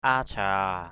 /a-d̪ʱia:/ (Skt.)
adhia.mp3